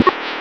chopperMineLoop.ogg